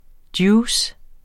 Udtale [ ˈdjuːs ]